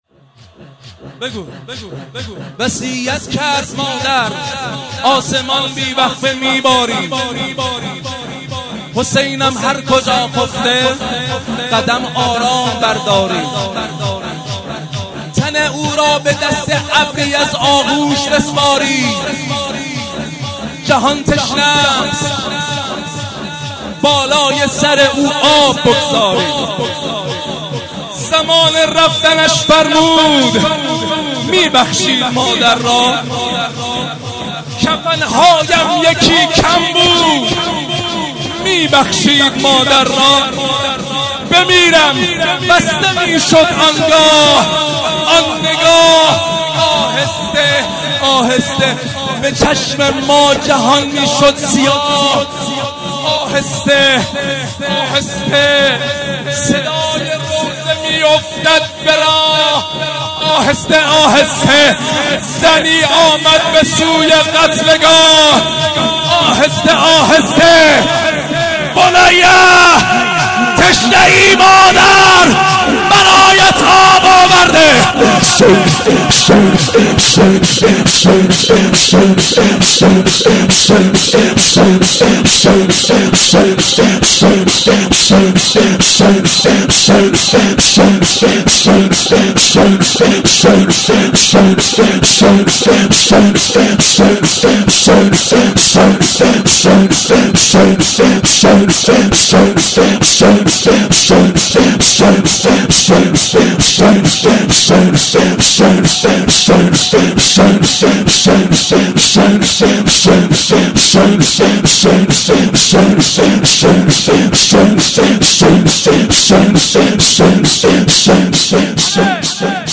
ذکر و شعر خوانی
شب دوم ویژه برنامه فاطمیه دوم ۱۴۳۹